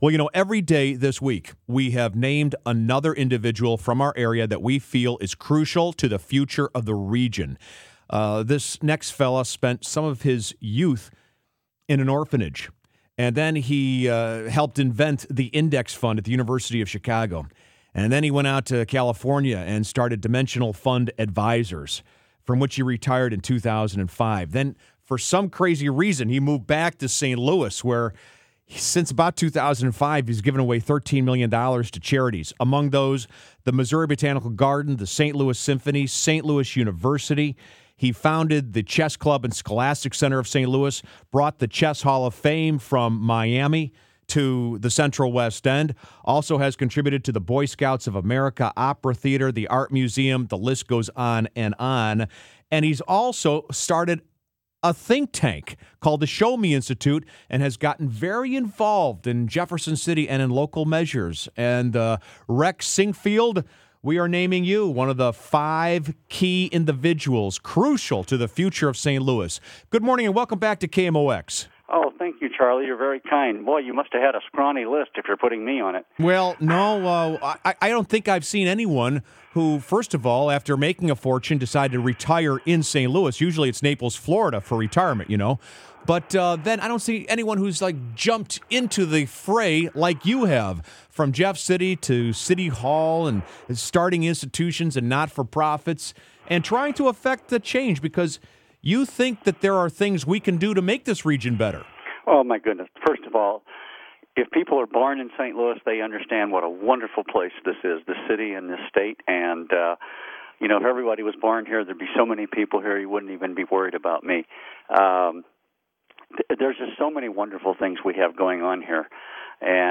on the Radio